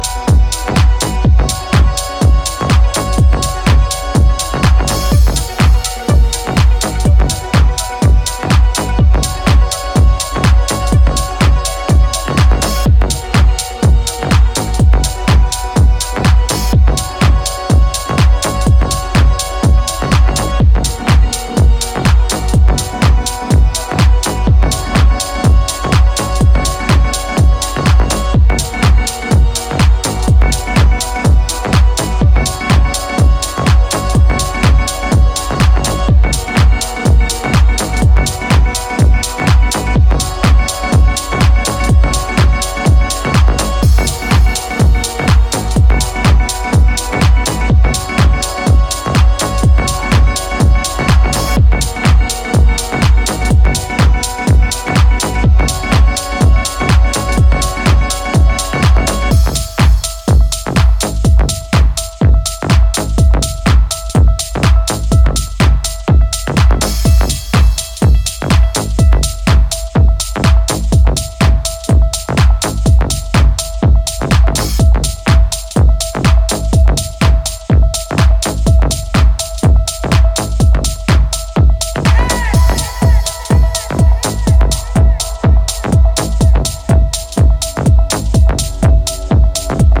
straight up house jams, hints of techno, nods to broken beat